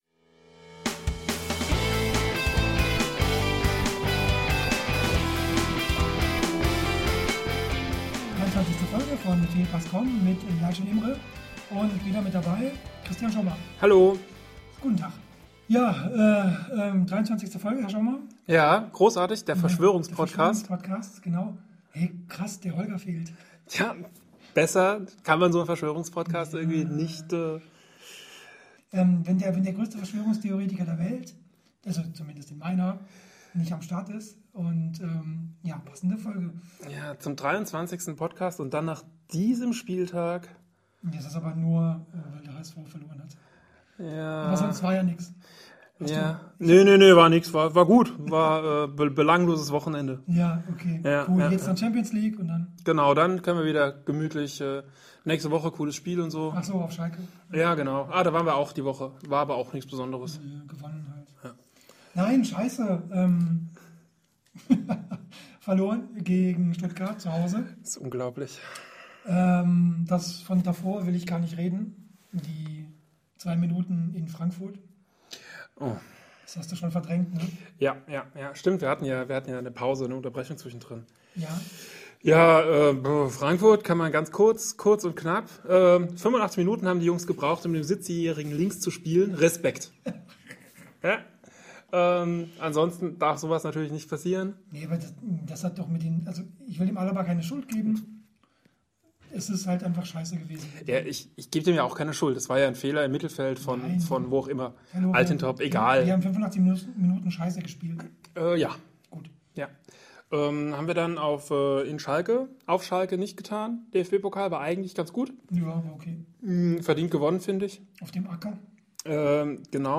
Wundert euch nicht über diverse Kommentare oder kleinere Tonprobleme.